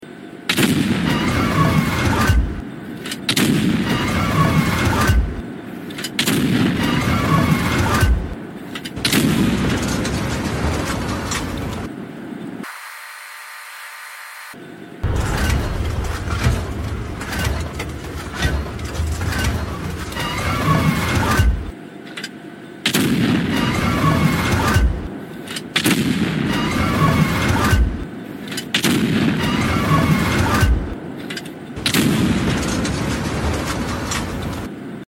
FV4005 Reloading Shells Sound (New)